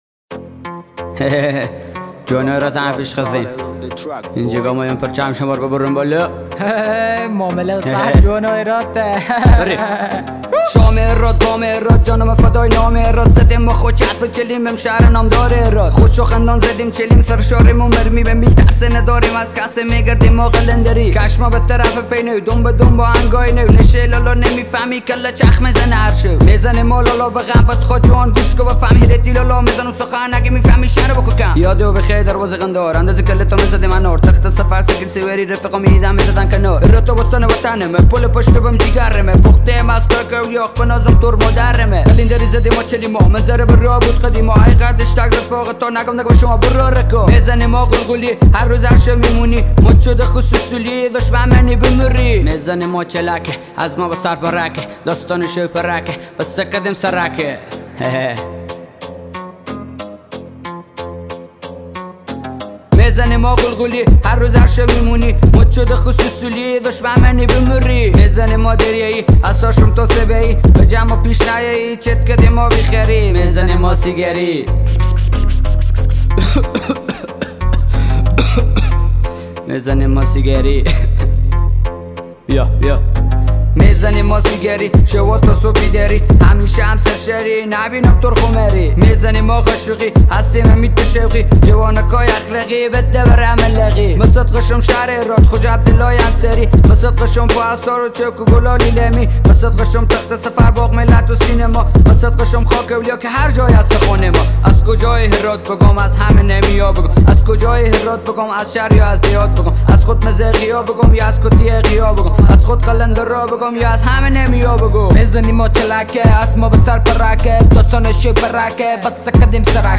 رپ افغانی